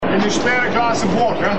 Said during torrential rain, naturally